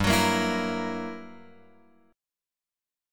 GmM13 chord {3 x 4 3 5 6} chord